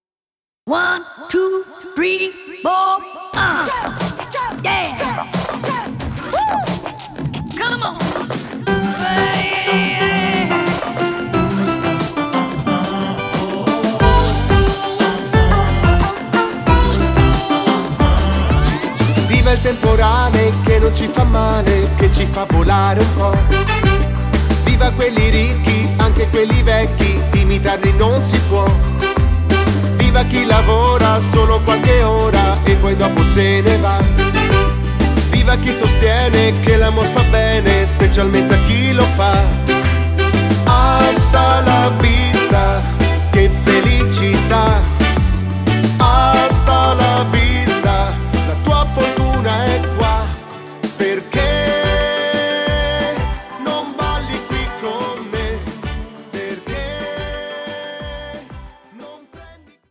Meneaito
12 Brani Editoriali + una cover di ballabili per Orchestra